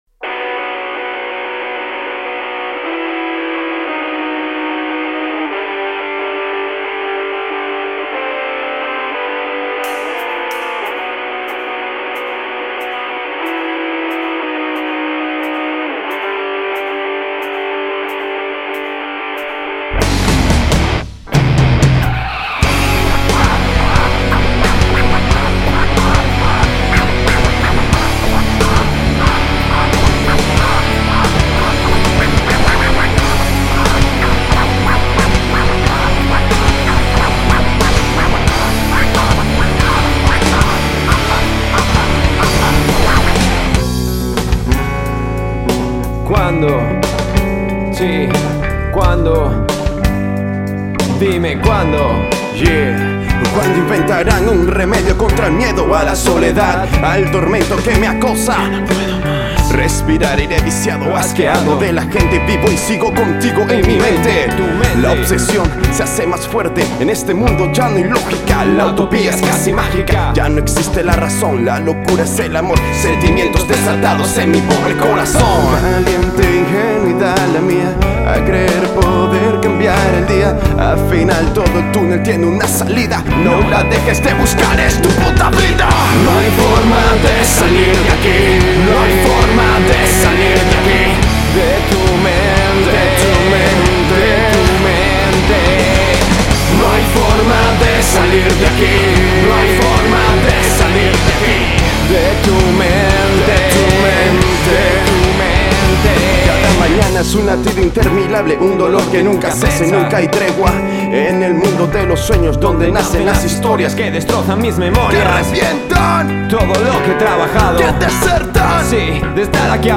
se mueve entre el metal, el hip-hop y el mundo alternativo
Guitarra y coros
Bajo
Batería